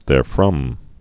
(thâr-frŭm, -frŏm)